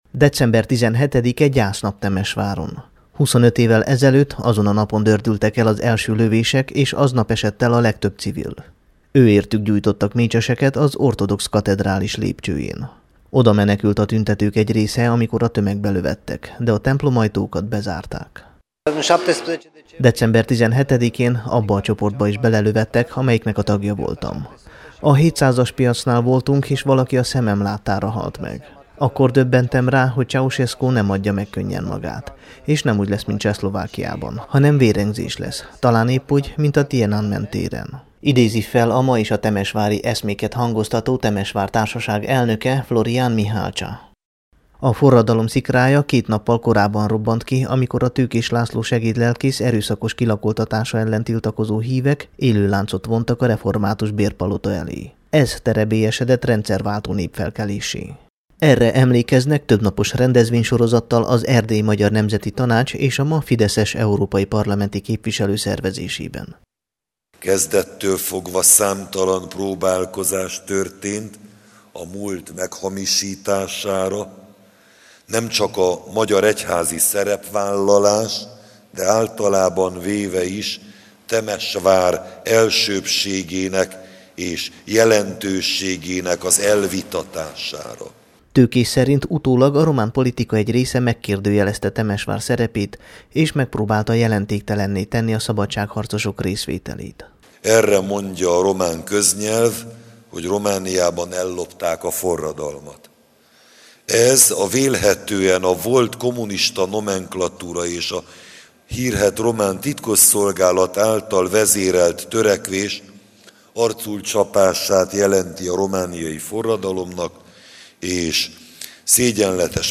temesvar_25_radio.mp3